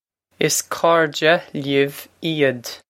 Pronunciation for how to say
Iss core-jeh lee-iv ee-od.
This is an approximate phonetic pronunciation of the phrase.